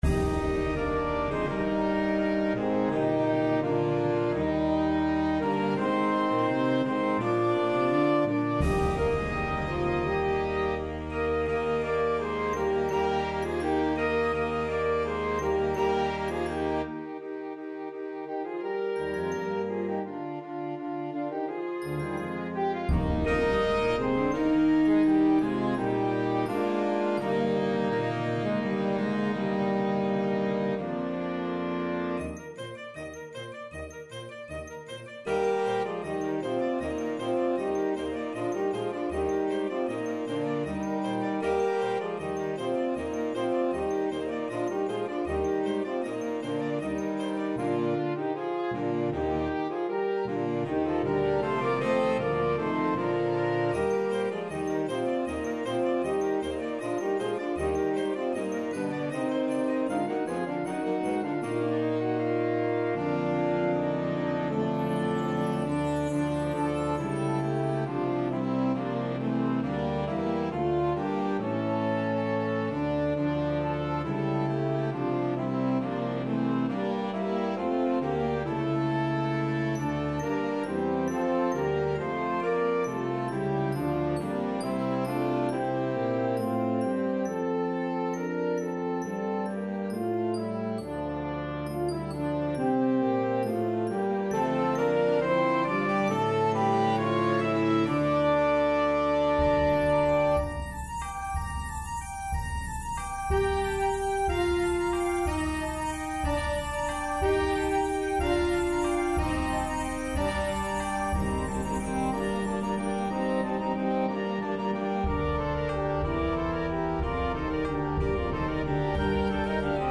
Flöte, Oboe, Klarinette
Klarinette/Trompete, Altsaxophon, Tenorsaxophon, Horn
Tenorhorn/Tenorsaxophon, Horn, Posaune/Bariton
Basskl., Baritonsaxophon, Tuba/Basspos./Bariton/Fagott
Schlagzeug (ad libitum)